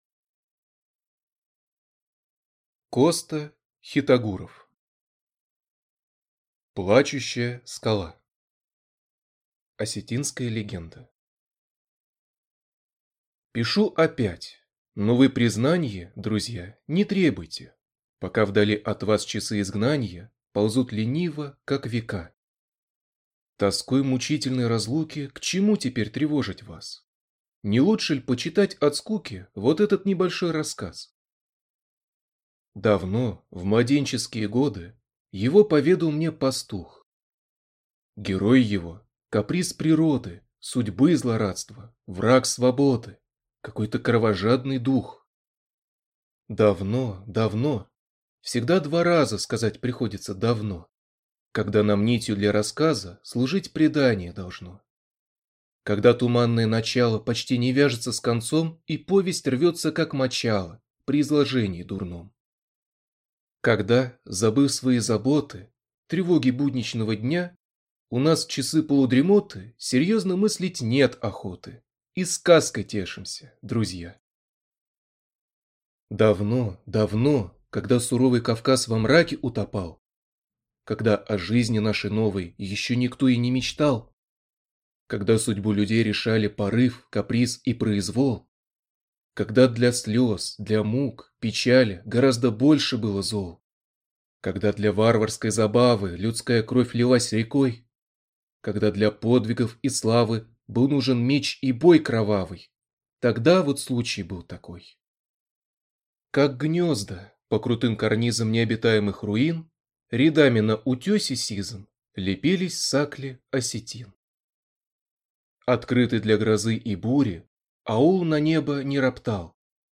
Аудиокнига Плачущая скала | Библиотека аудиокниг